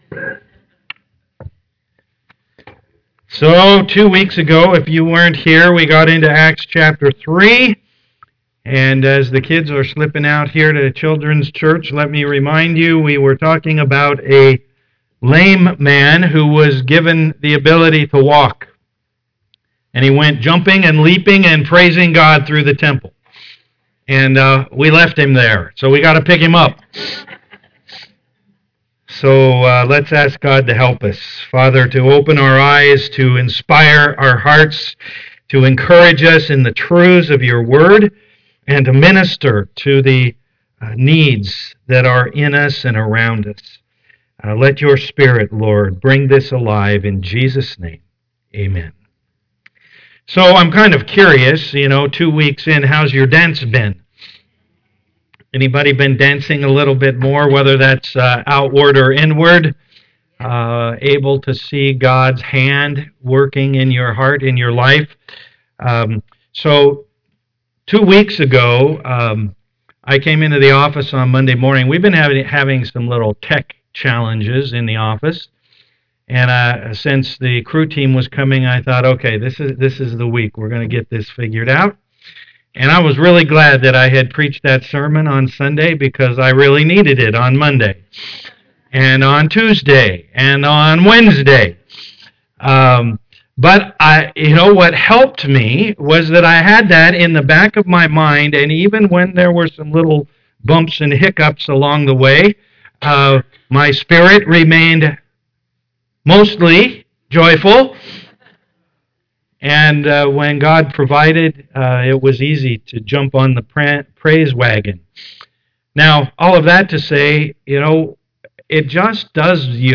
Passage: Acts 3:11-26 Service Type: am worship